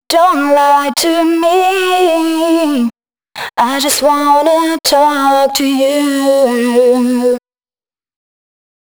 009 female.wav